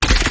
RobotCrush1.mp3